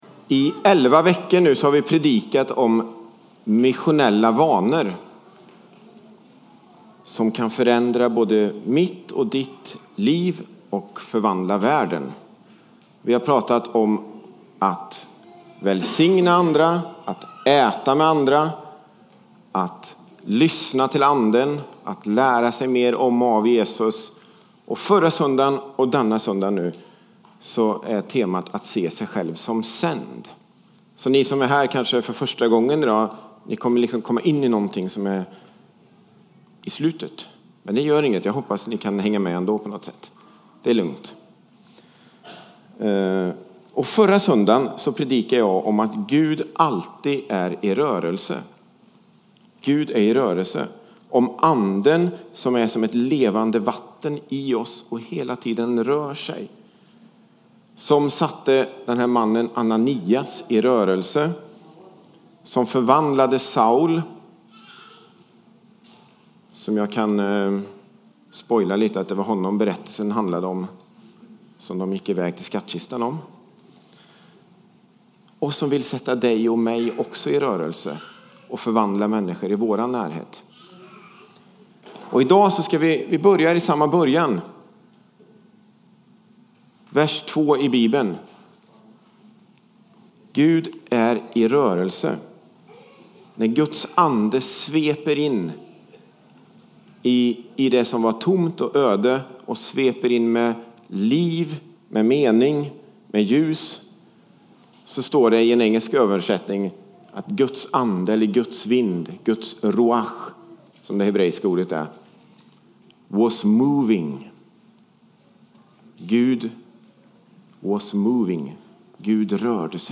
A predikan from the tema "Fristående HT 2018."